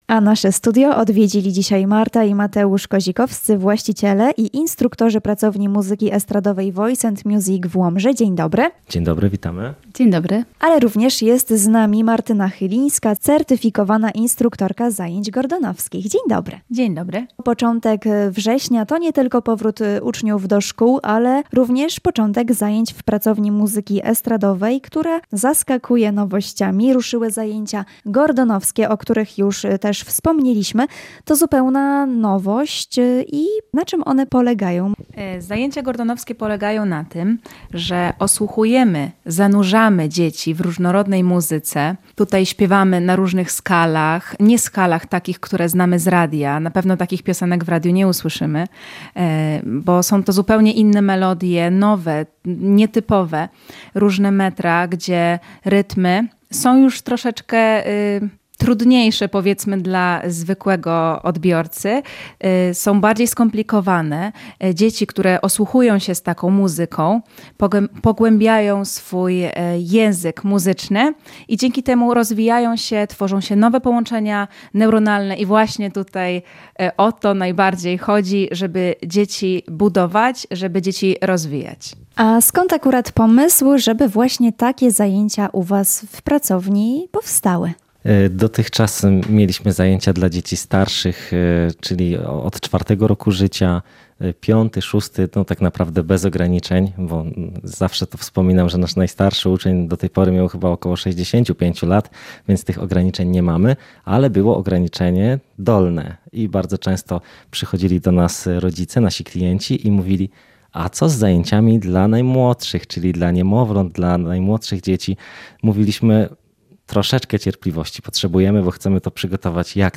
Rozmowa RN